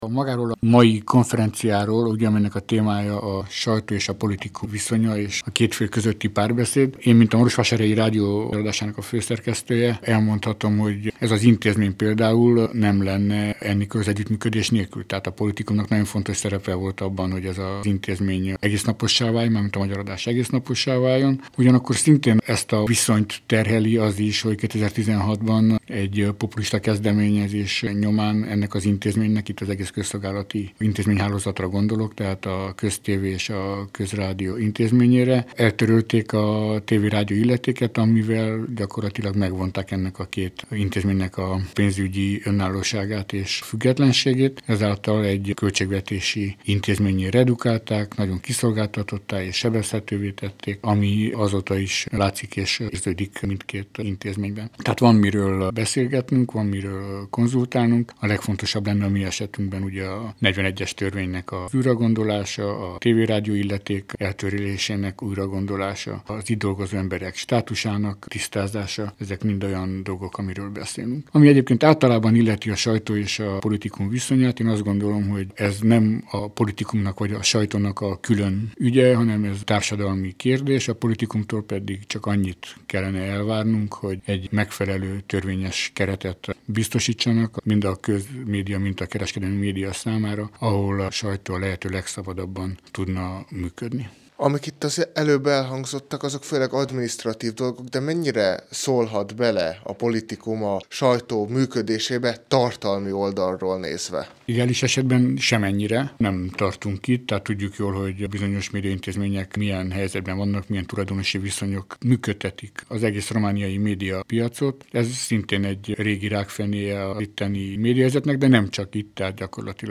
Kerekasztal-beszélgetés a politikum és a sajtó között